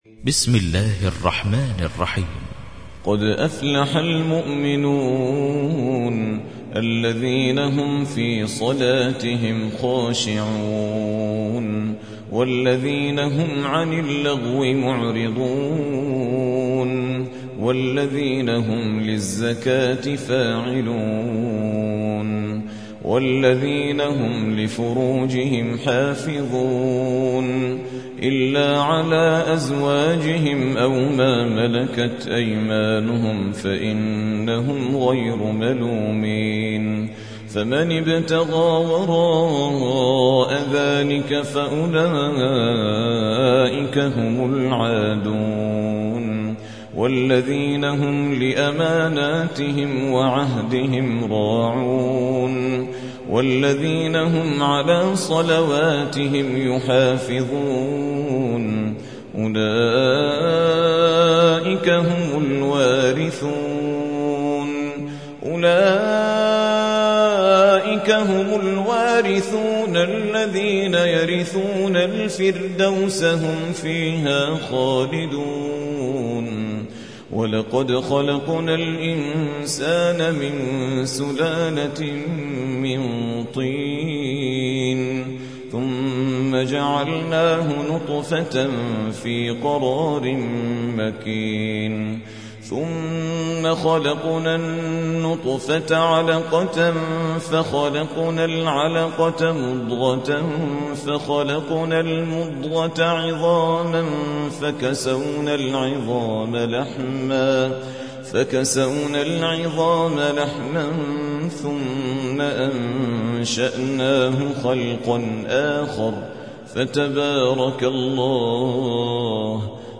23. سورة المؤمنون / القارئ